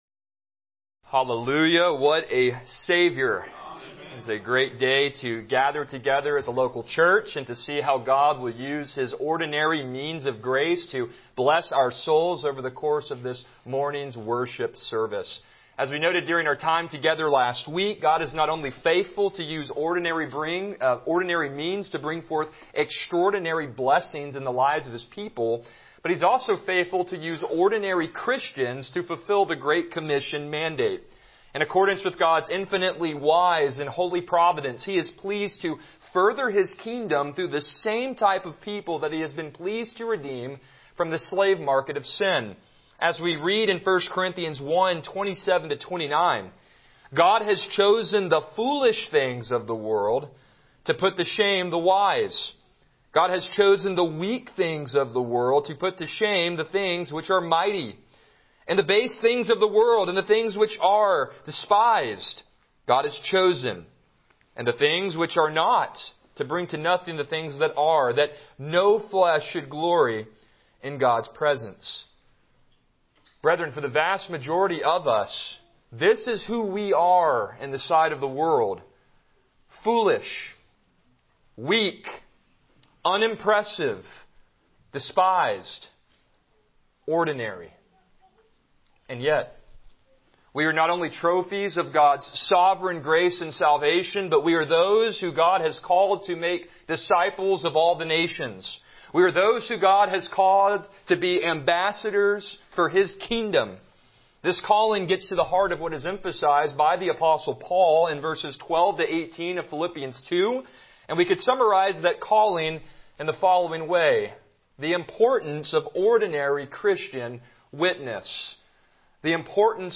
Passage: Philippians 2:14 Service Type: Morning Worship